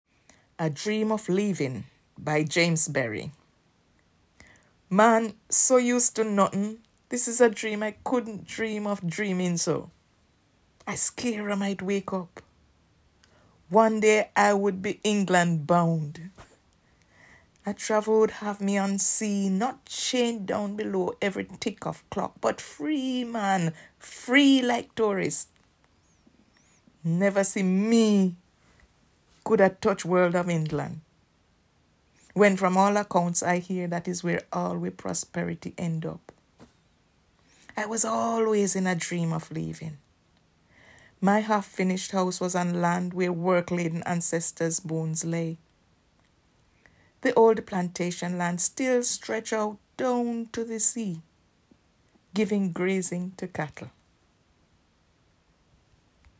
A Dream of Leavin by James Berry read by Valerie Bloom
A-Dream-of-Leavin-by-James-Berry-read-by-Valerie-Bloom.m4a